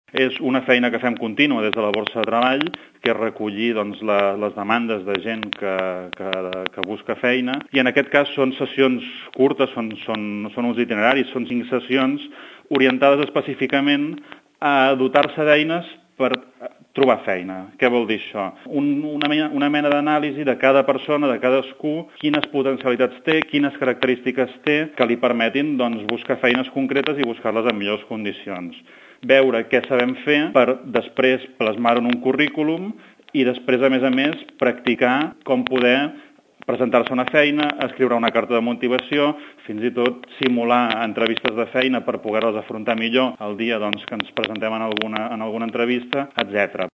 Les sessions s’organitzen amb l’objectiu que els usuaris aprenguin a desenvolupar les competències personals i laborals per millorar les possibilitats de trobar una feina, des de la base del projecte professional, passant pel capital, el currículum i l’entrevista competencial. Ho explica el regidor de promoció econòmica, Marc Unió.